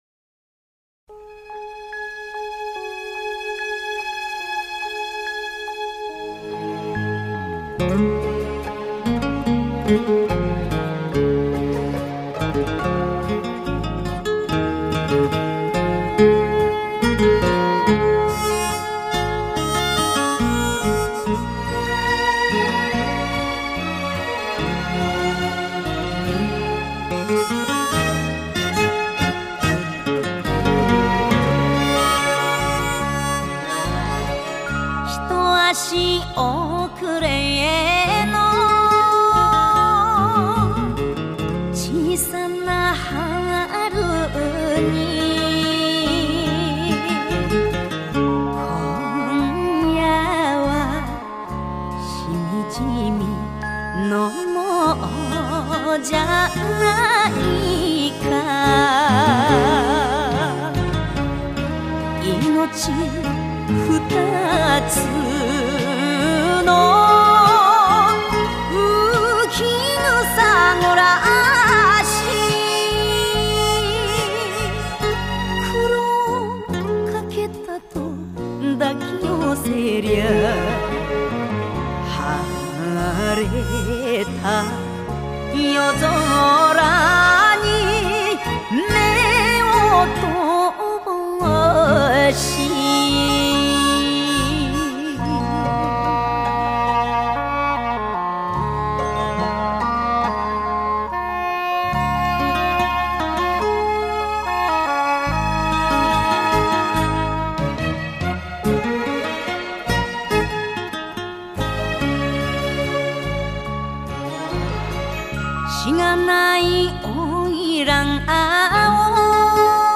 演歌歌手。